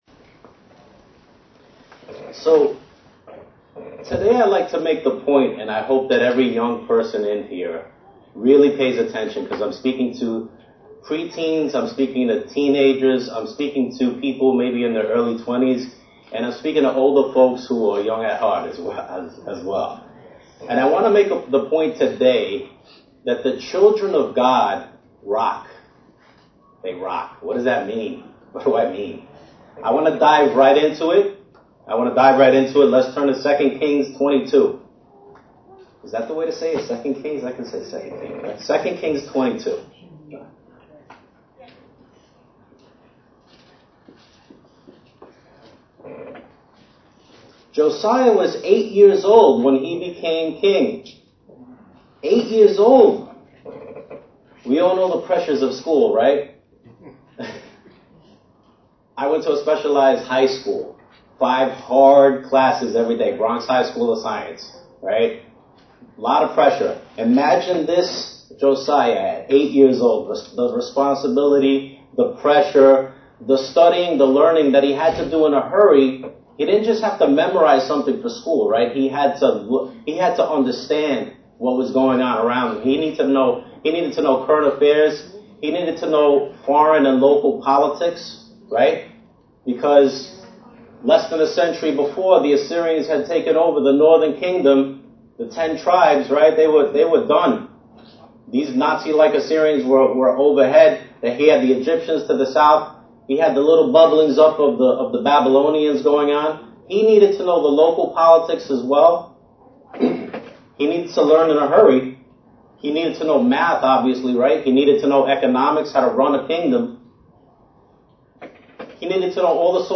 NYC's monthly Youth Day sermon. When the world seems like it has abandoned God, we can be spiritual rocks, built upon our cornerstone, able to dodge boulders and move mountains of problems.